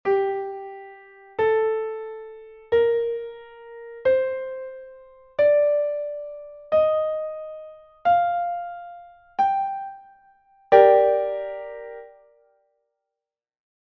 escala_sol_menor.mp3